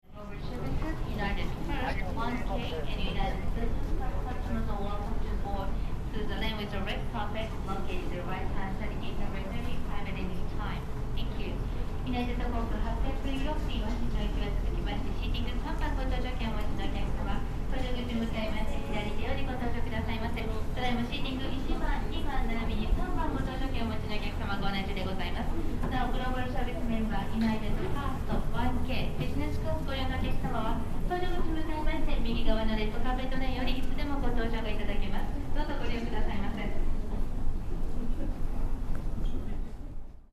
今のところほぼ生音。
搭乗手続き ユナイテッド航 空便に乗りました（女声） 00.40